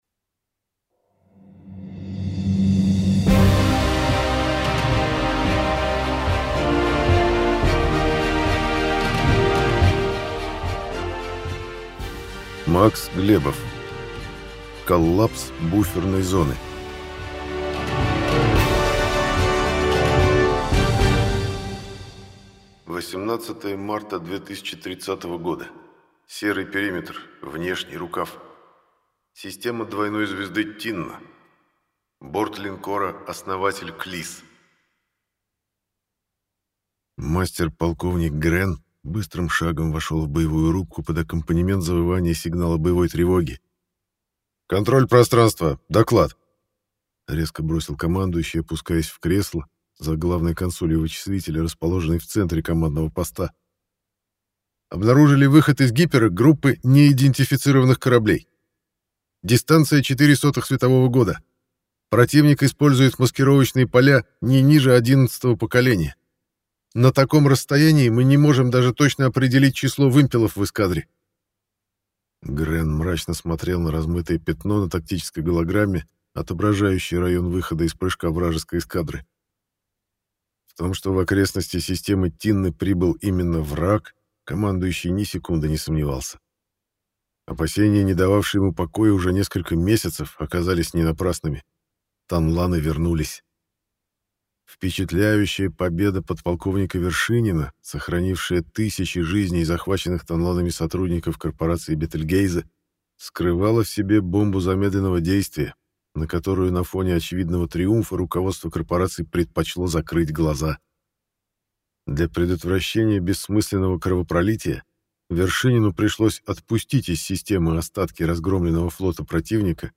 Аудиокнига Звезд не хватит на всех. Коллапс Буферной Зоны | Библиотека аудиокниг